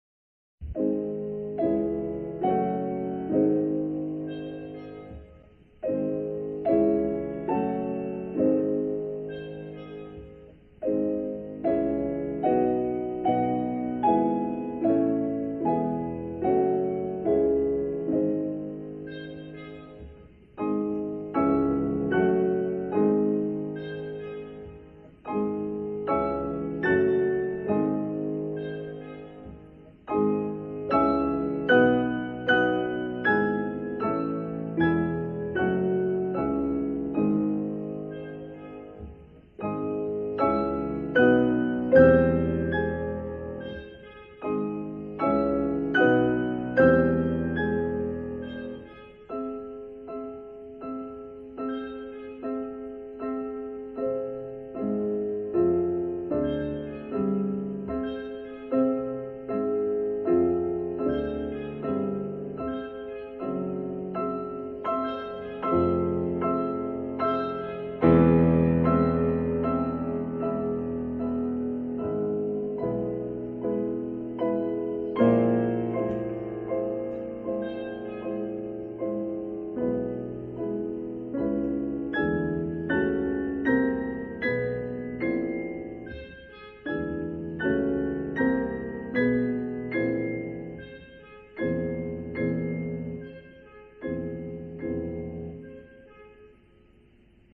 Композитори дуже люблять передавати кування зозулі.